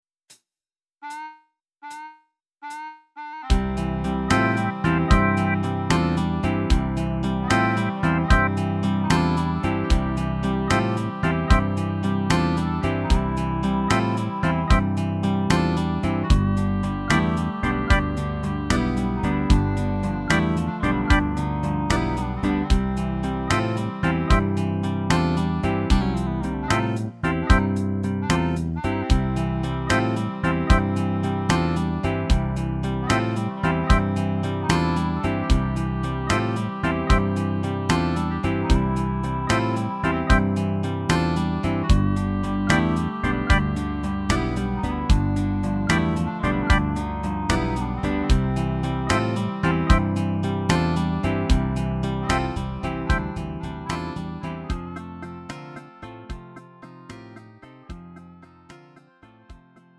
음정 -1키
장르 구분 Lite MR